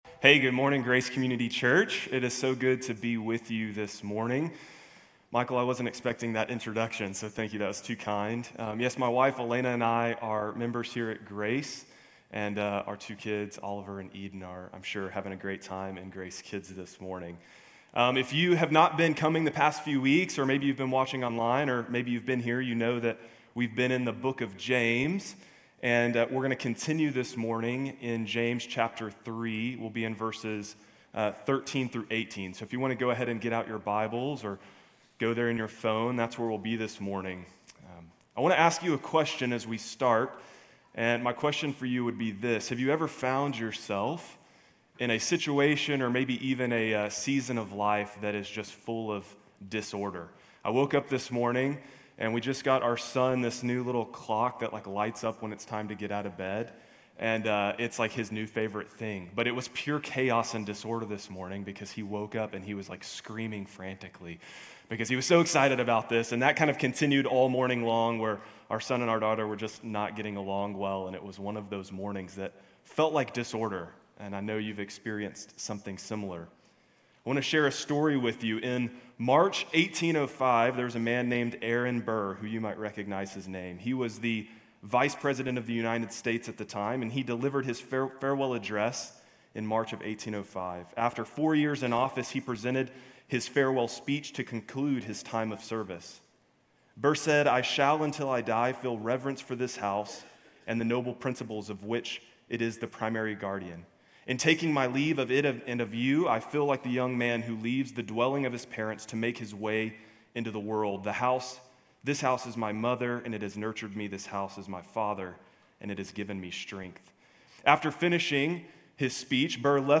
GCC-UB-July-16-Sermon.mp3